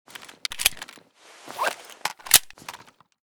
kiparis_reload.ogg